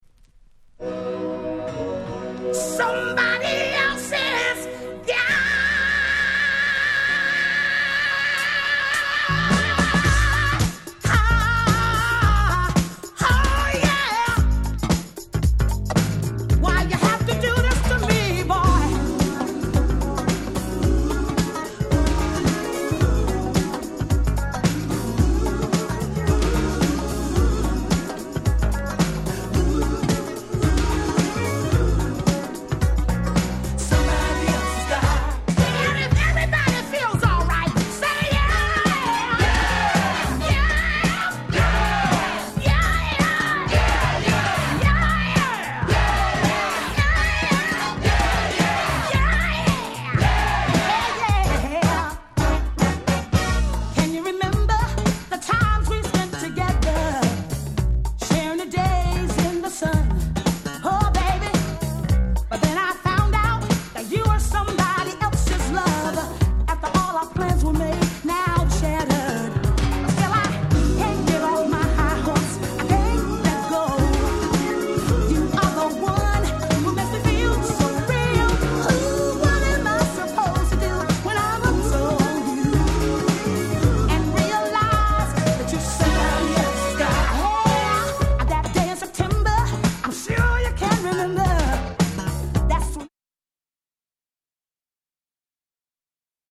鉄板Dance Classic !!
南国を彷彿とさせるアレンジが気持ち良すぎます！！